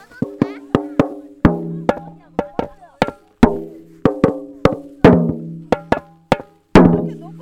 북치기02.mp3